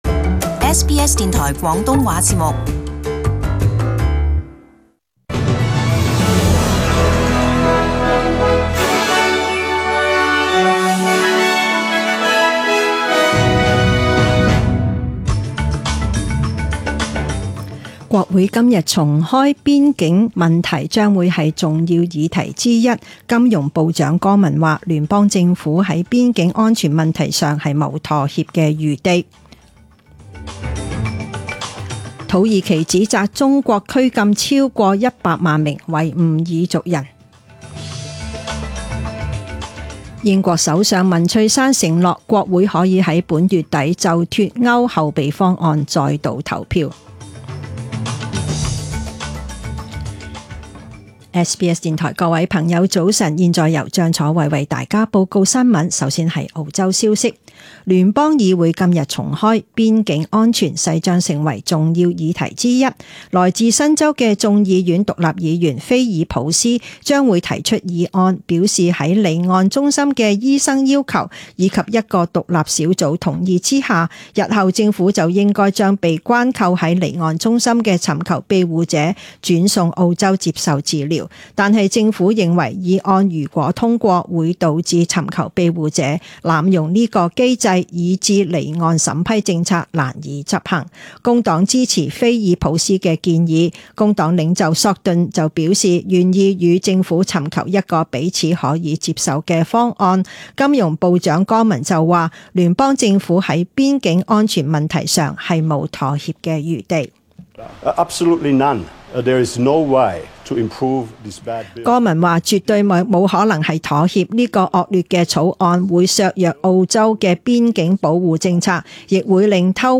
Chinese (Cantonese) News Source: SBS News